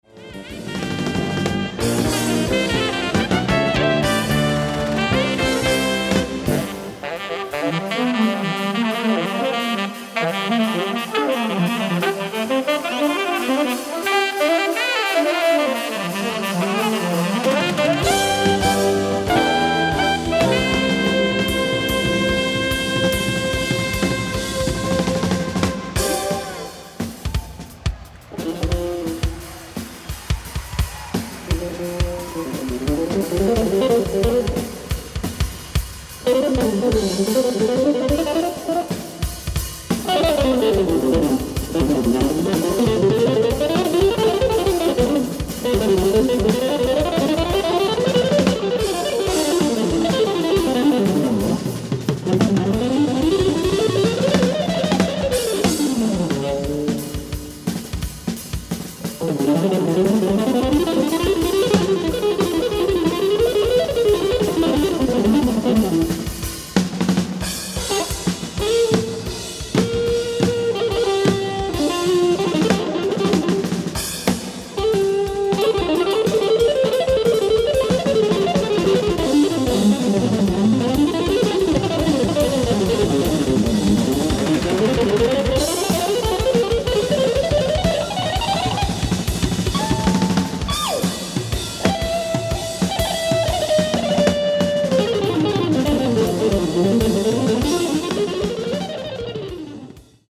ライブ・アット・コンツェルトハウス、ウィーン、オーストリア 02/10/1985
今年再放送されたピカピカ音質収録！！